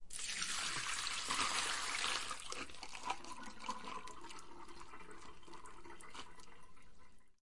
冰 " 水冰冷却器把装满冰的冷却器倒在混凝土上
描述：将一个装满冰块的冷却器倾倒在混凝土上。 用Tascam DR40录制。
标签： 寒冷 潮湿 撞击 飞溅 混凝土 倾倒 倾倒 冰块 转储 崩溃 冰块 影响
声道立体声